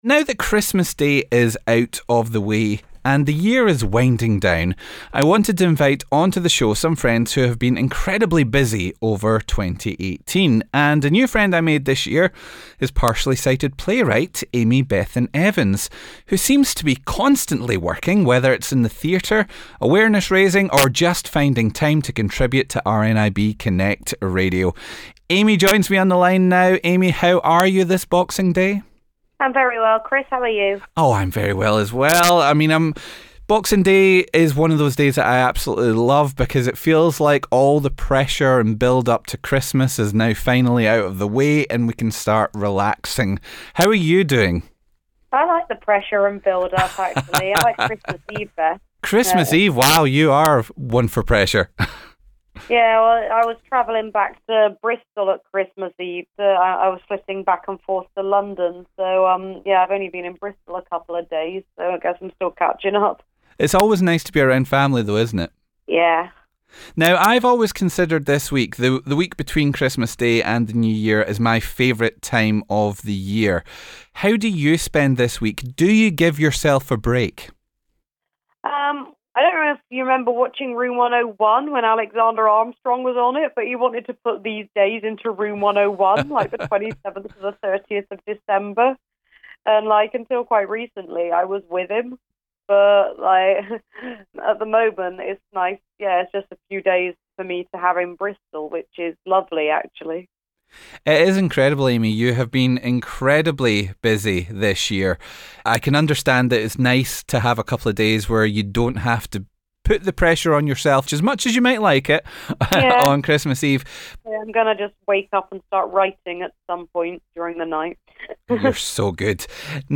RNIB Connect Radio